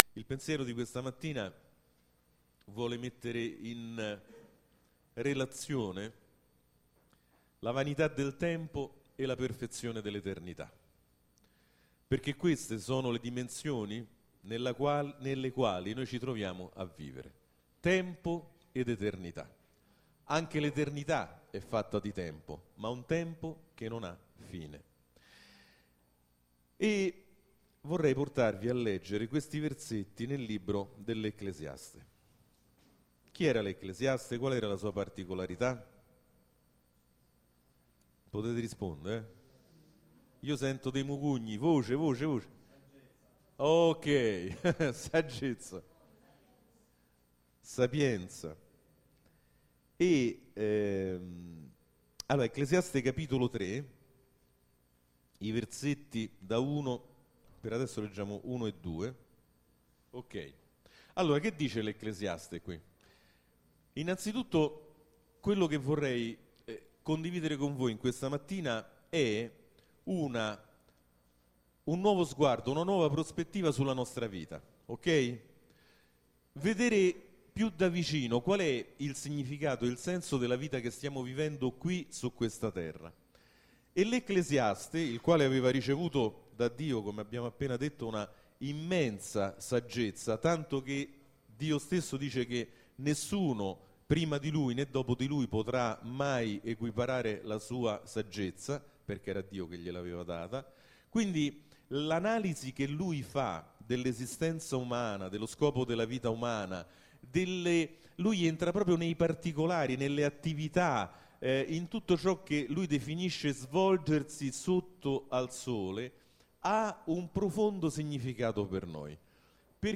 Sermoni della domenica